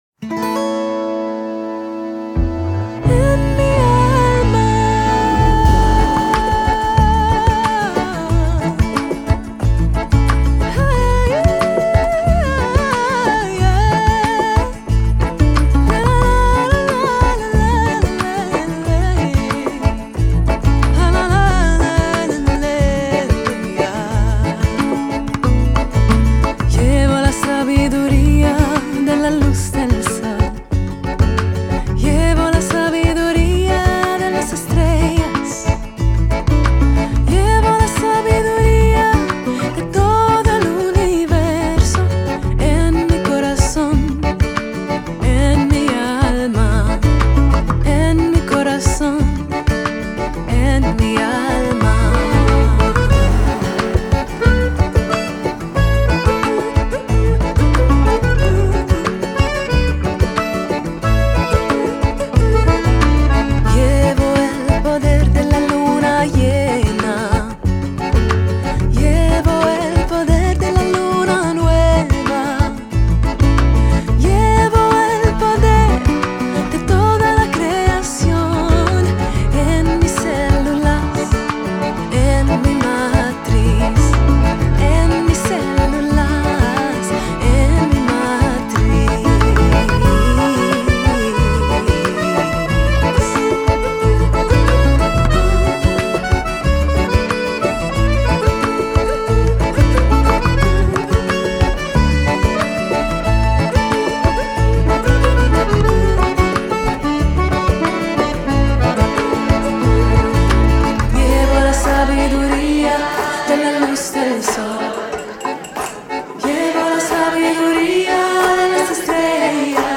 Schweizer Weltmusik